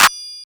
SOUTHSIDE_clap_mpc_days.wav